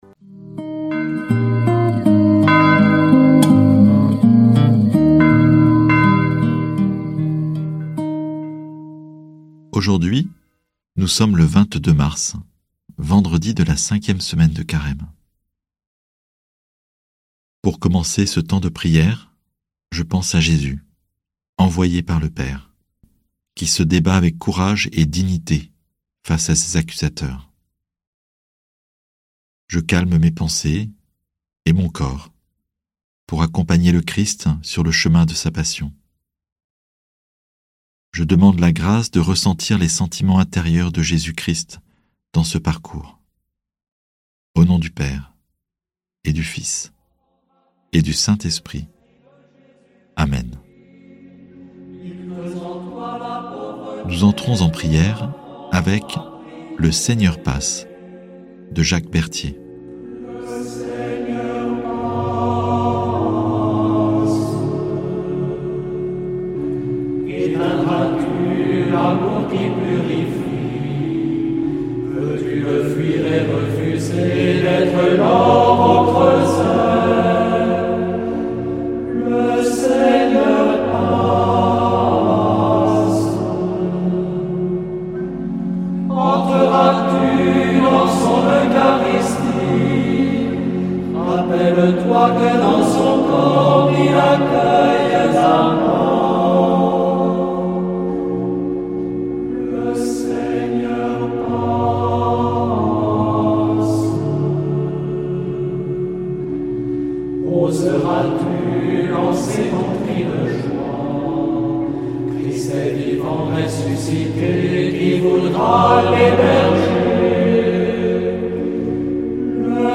Prière audio avec l'évangile du jour - Prie en Chemin